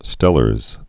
(stĕlərz)